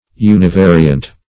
Search Result for " univariant" : The Collaborative International Dictionary of English v.0.48: Univariant \U`ni*va"ri*ant\, a. (Chem.) Having one degree of freedom or variability.